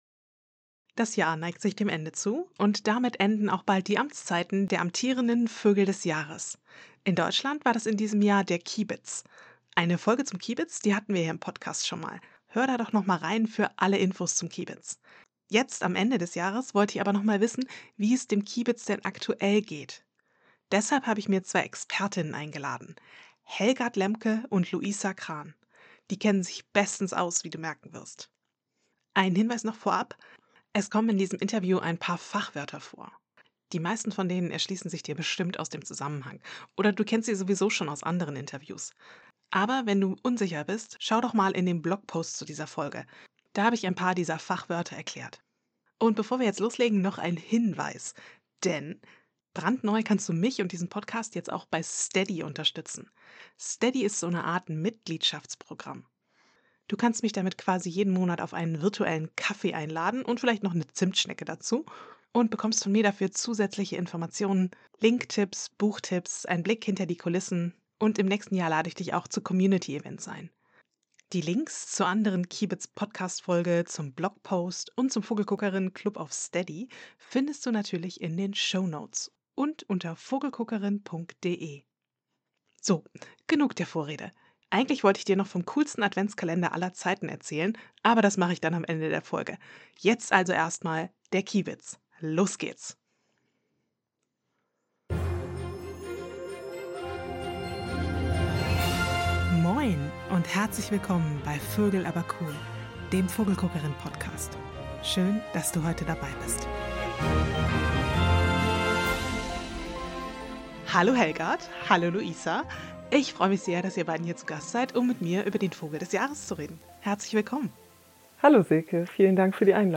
Wie geht's eigentlich dem Kiebitz? Interview
Sie arbeiten im Wiesenvogelschutz und sind echte Expertinnen für den Kiebitz.